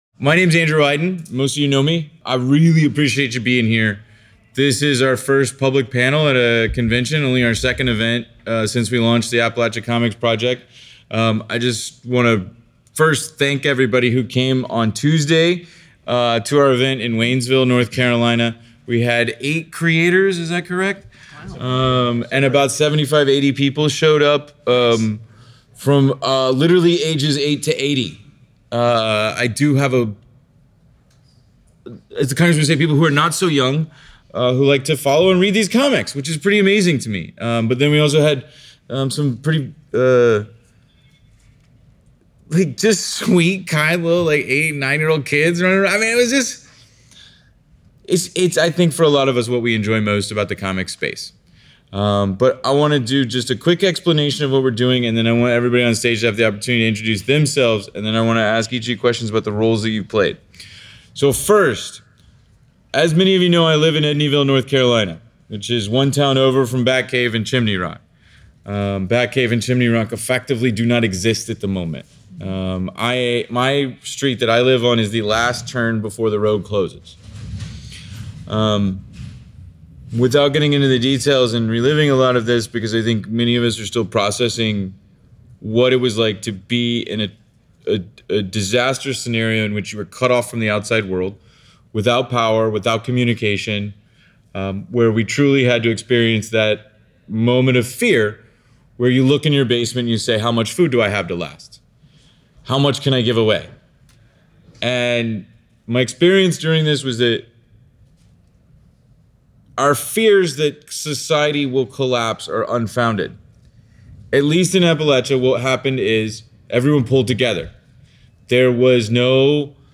HeroesCon 2025 Panel - Appalachia Comics Project — Dollar Bin Comics